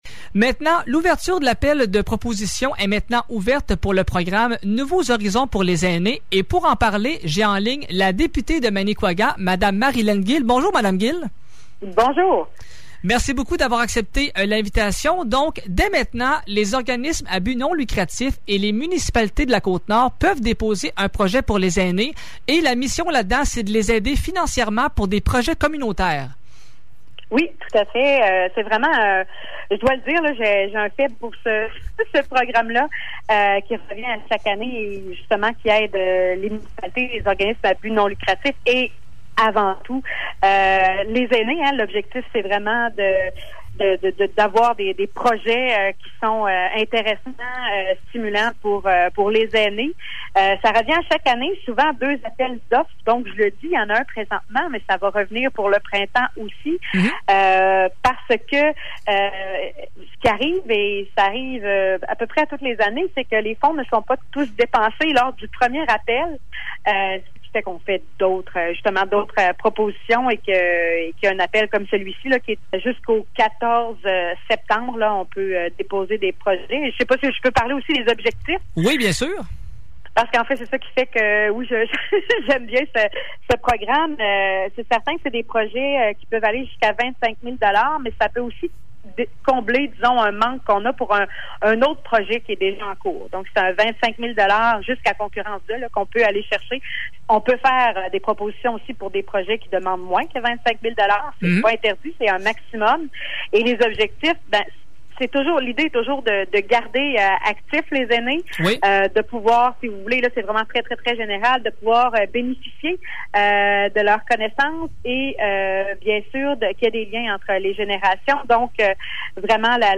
Voici l'entrevue avec la députée de Manicouagan, Marilène Gill :
Entrevue-Gill-Programme-Aines-CI.mp3